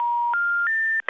Звук отсутствия соединения без голоса оператора